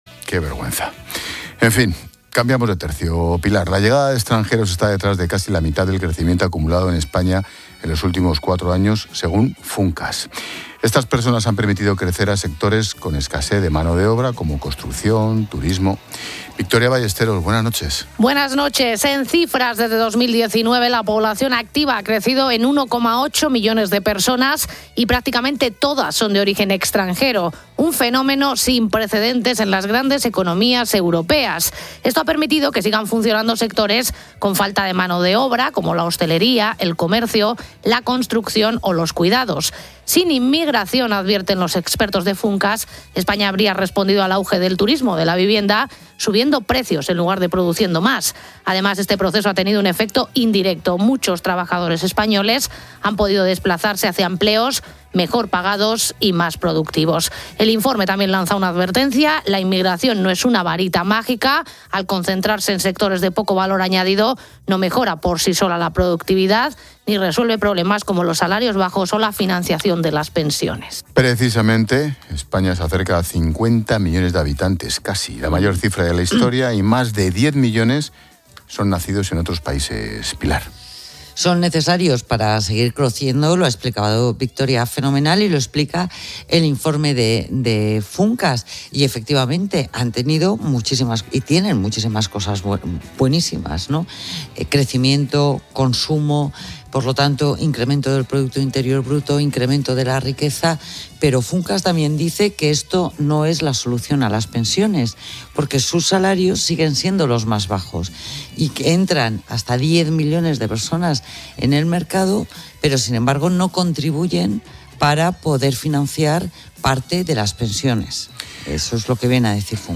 Expósito aprende en Clases de Economía de La Linterna con la experta económica y directora de Mediodía COPE, Pilar García de la Granja, sobre el impacto de la inmigración en la economía y demografía española